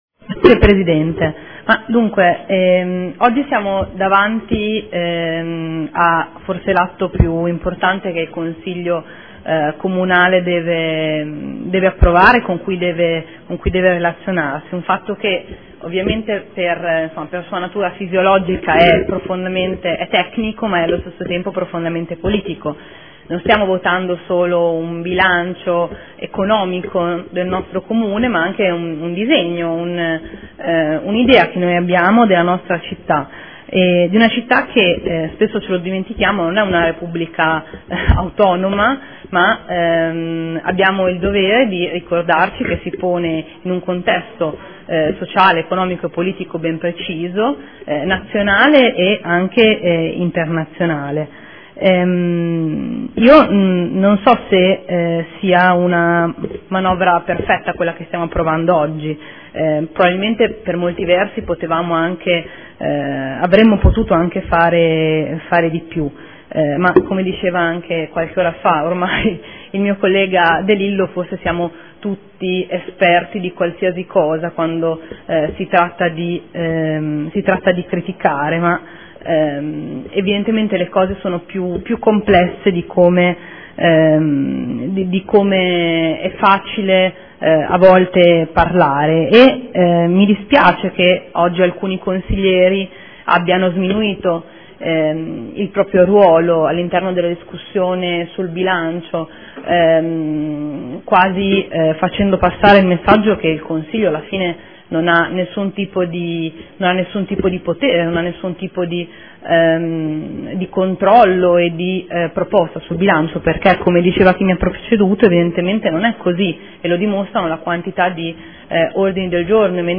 Seduta del 05/03/2015 Dibattito sul Bilancio, sulle delibere, odg ed emendamenti collegati